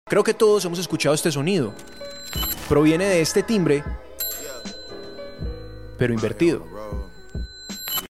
👇🏻 Este tipo de audios se denominan “Risers” nos ayudan a generar tensión en momentos clave para reforzar la narrativa y destacar contenido específico.